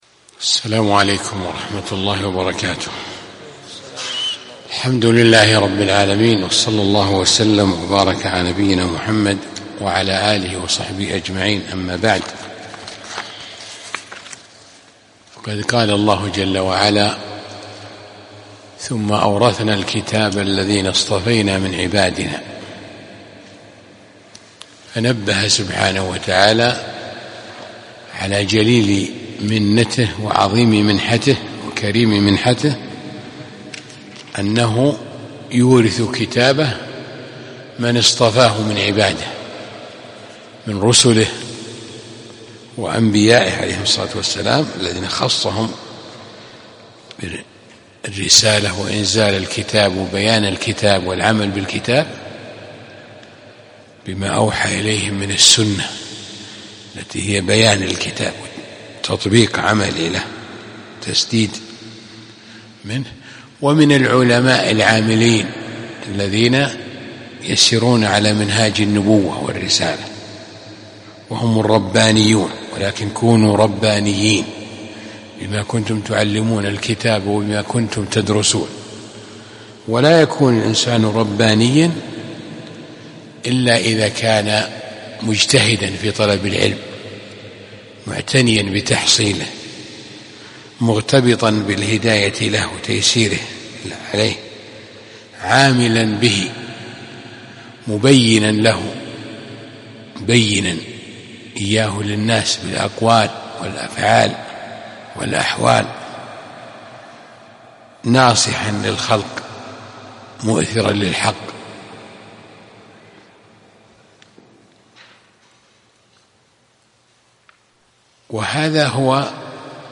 كلمة قبل الدرس الثالث من شرح السنة للمزني